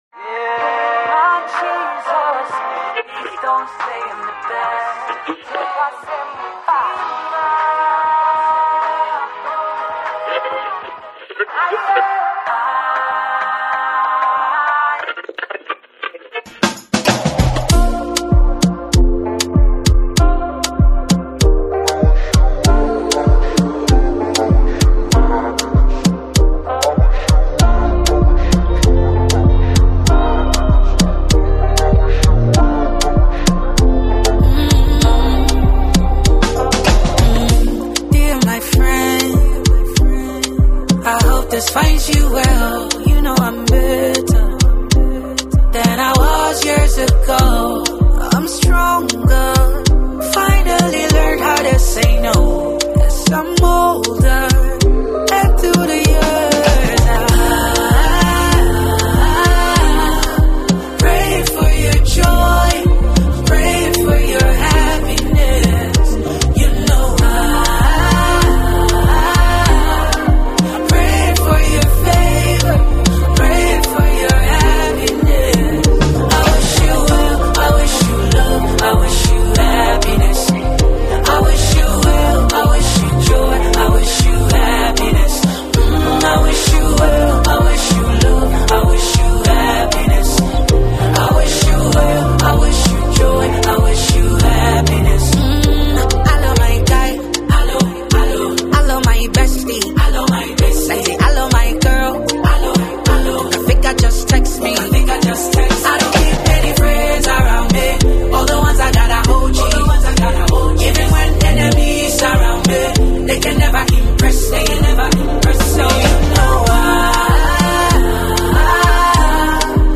rap gospel